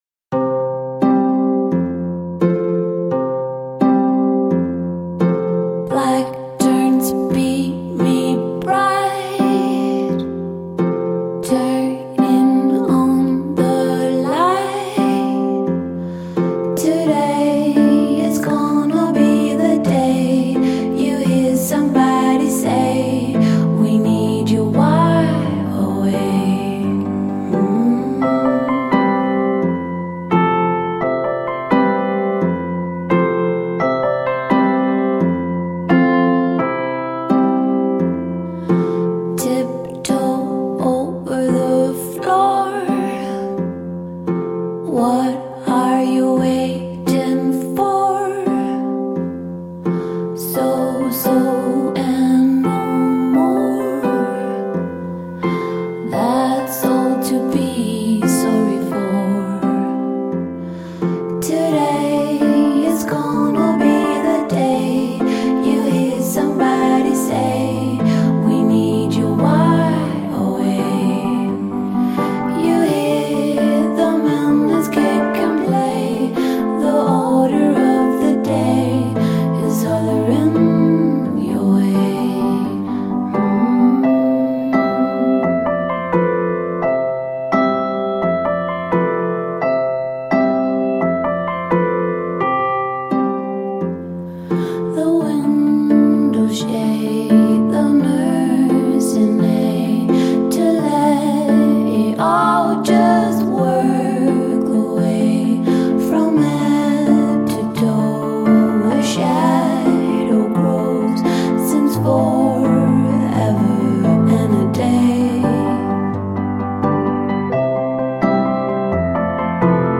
mit einer herrlich sanften Stimme mit Hang zur Melancholie